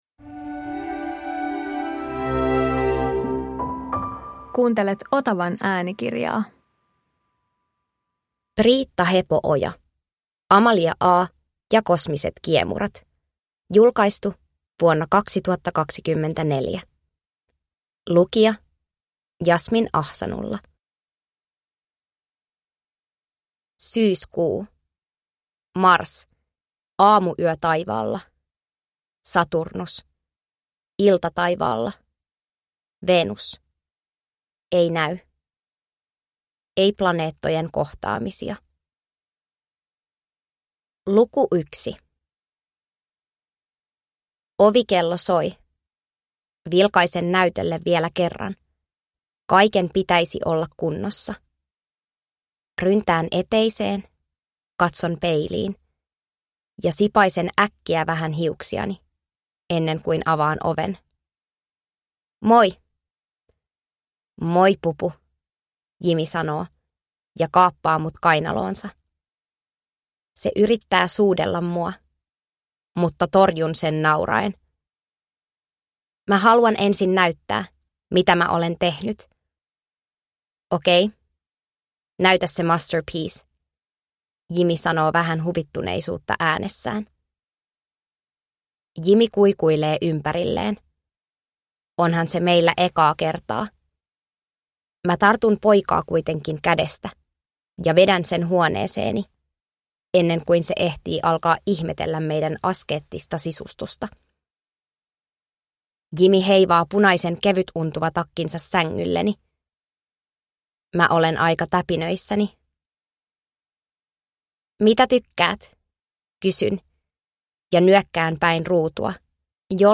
Amalia A ja kosmiset kiemurat (ljudbok) av Briitta Hepo-oja